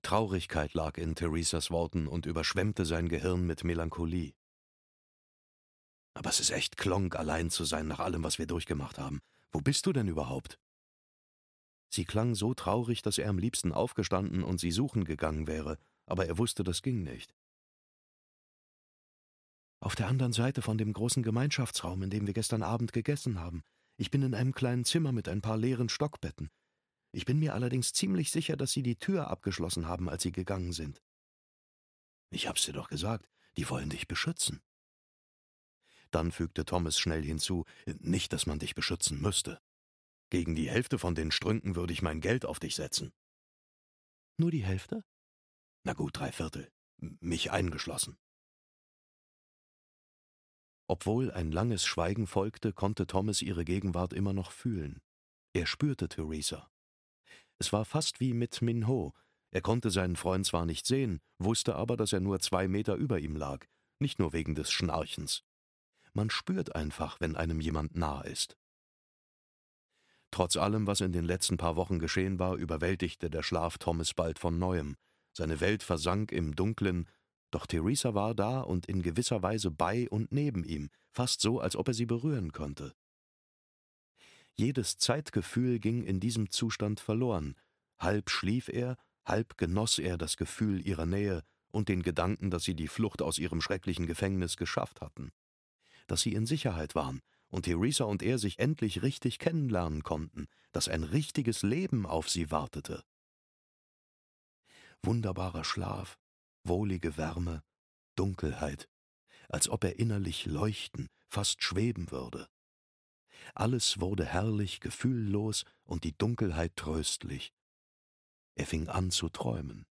Hörbuch Die Auserwählten - Maze Runner 2: Maze Runner: Die Auserwählten - In der Brandwüste, James Dashner.